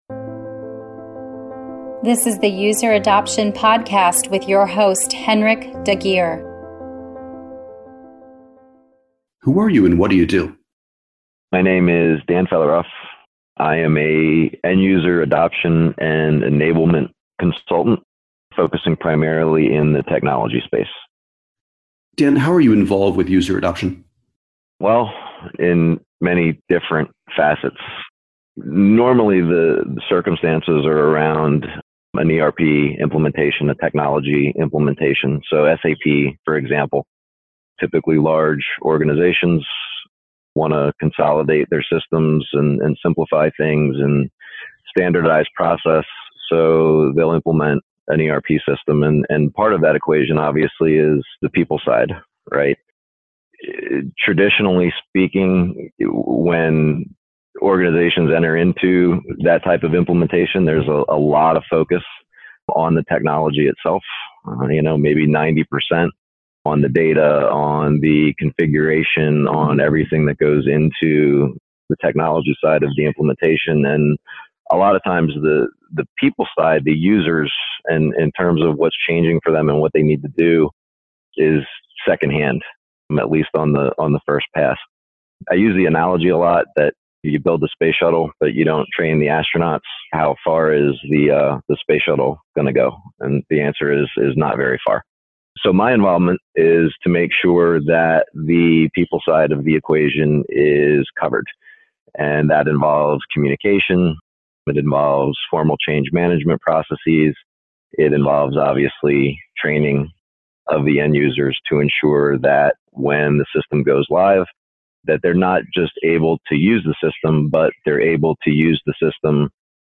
User Adoption / Interview